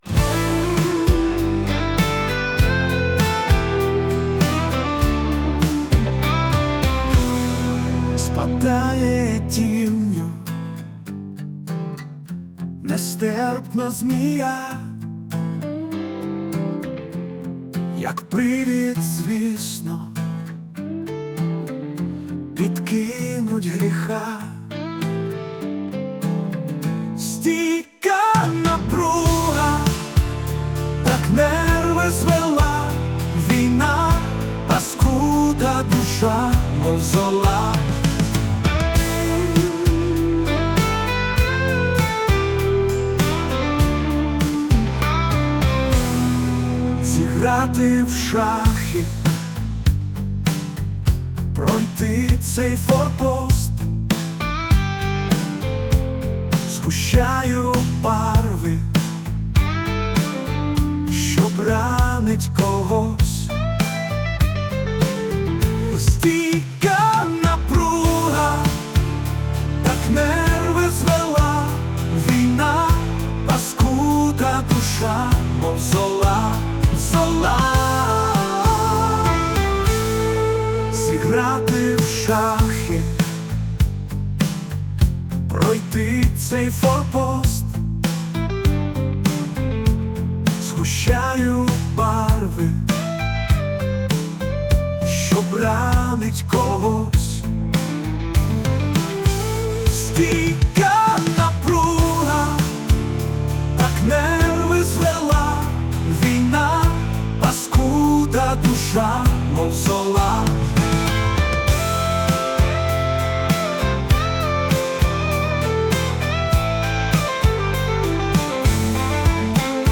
Музична композиція створена за допомогою SUNO AI
Дуже чутлива композиція...